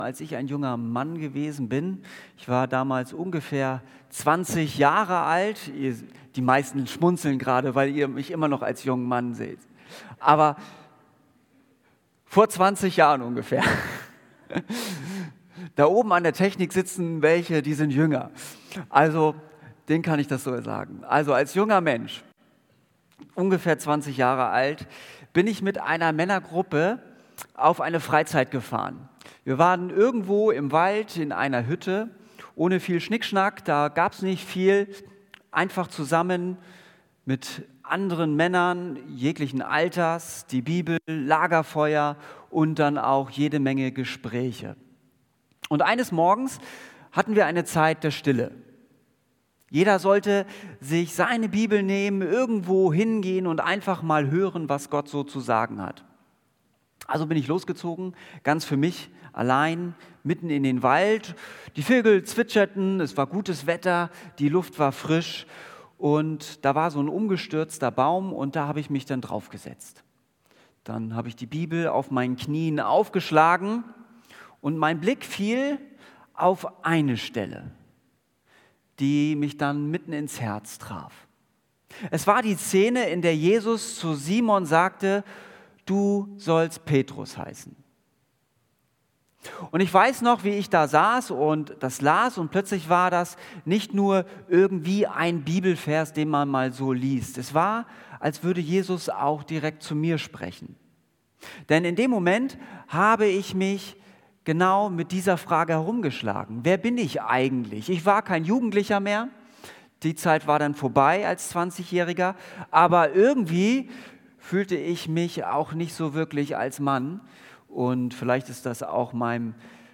Predigt Wenn Gott spricht